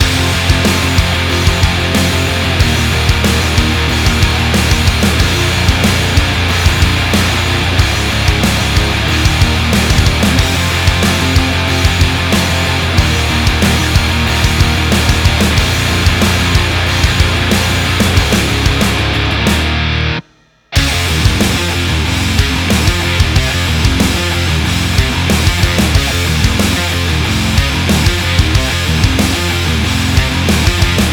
Metal - Circle The Drain_6Ni4KyAtup.wav